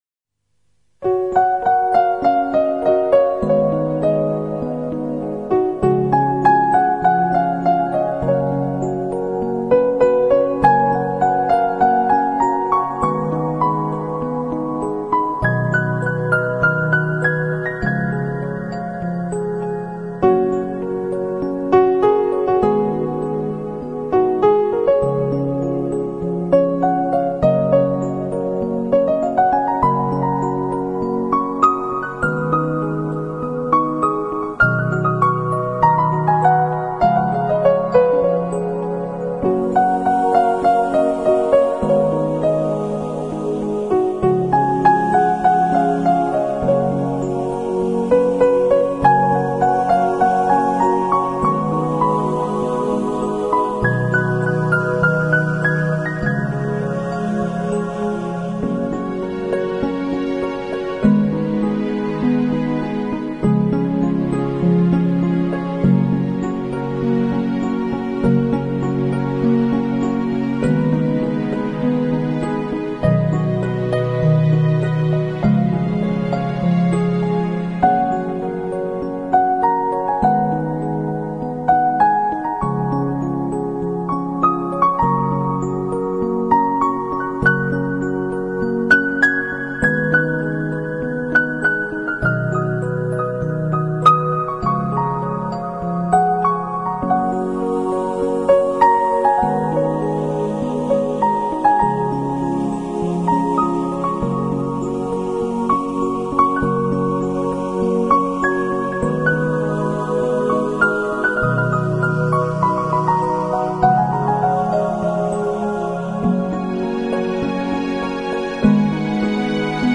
由于曲目较长，附第一首曲目开头试听片断